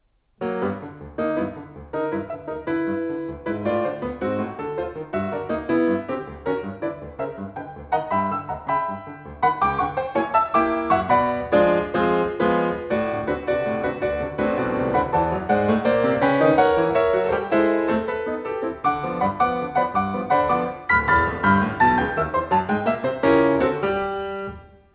piano)hu